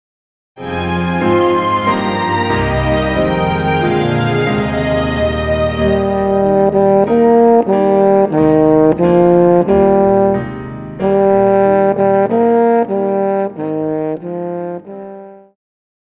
Classical
French Horn
Band
Hymn,Classical Music
Instrumental
Only backing